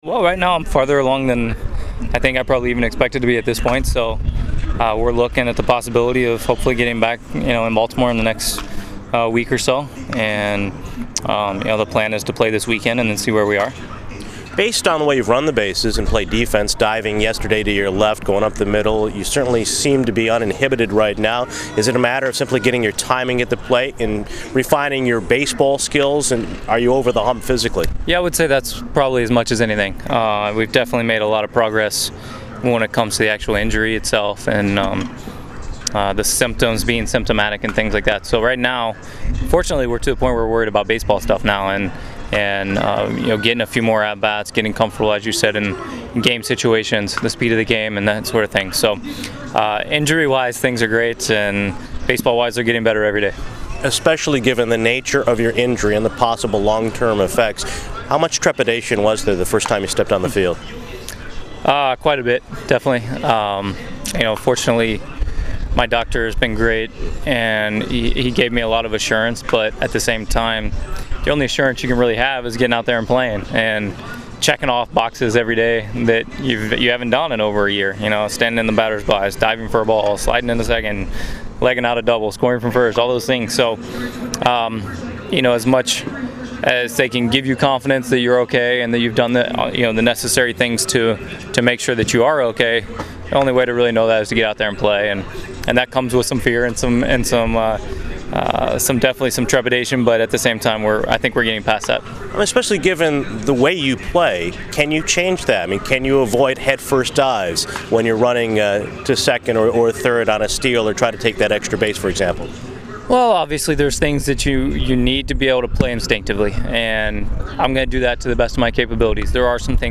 Baltimore Oriole Brian Roberts, continuing a major league injury rehabilitation assignment, appeared as a guest Friday on the Norfolk Tides pre-game show from Rochester, N.Y.  Entering Saturday’s series opener at Buffalo, the 34-year-old Roberts, out since last May due to post-concussion syndrome, is 3-for-13 with two doubles and two runs scored for Norfolk.
brianrobertsintvw.mp3